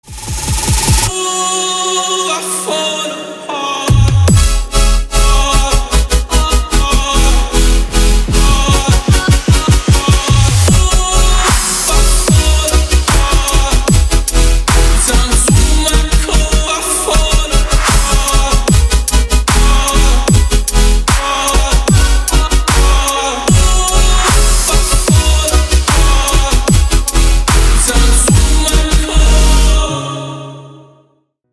• Качество: 320, Stereo
мужской голос
громкие
dance
Electronic
электронная музыка
Trap
Стиль: future bass, midtempo